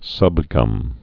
(sŭbgŭm)